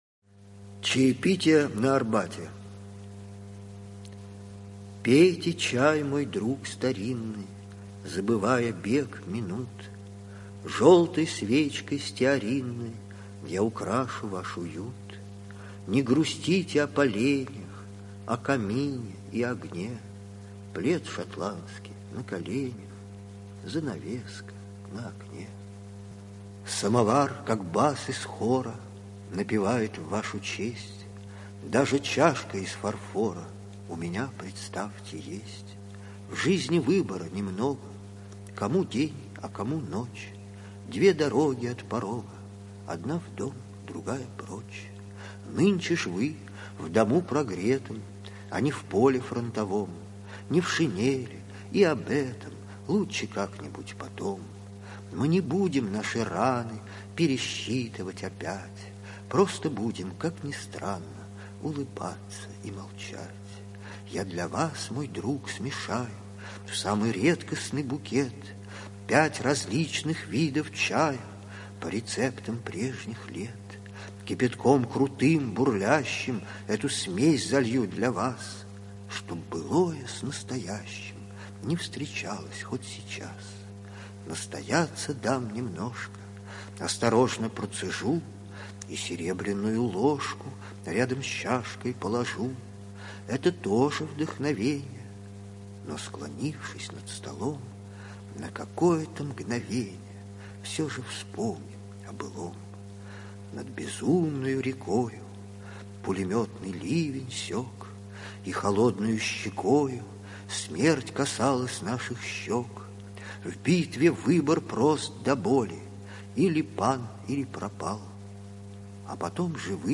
okudzhava-chitaet-svoi-stihi-chaepitie-na-arbate